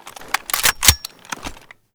vz58_unjam.ogg